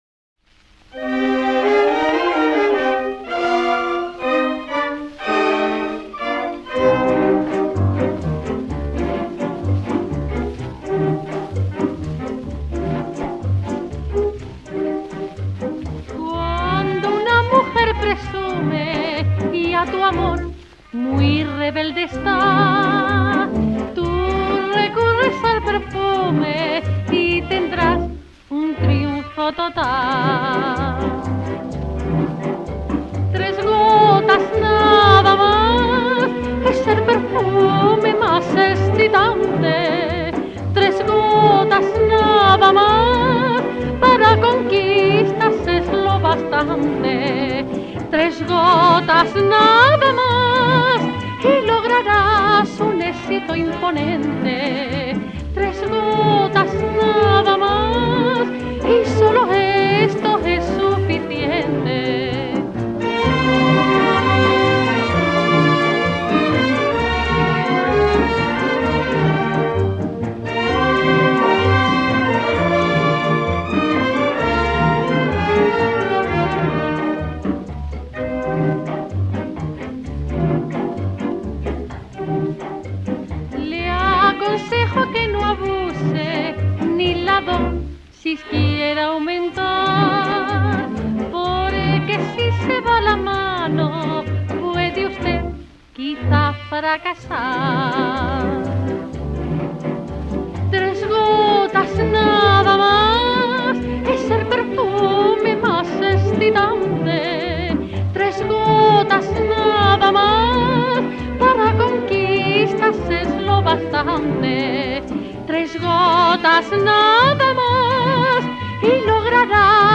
Bolero.
78 rpm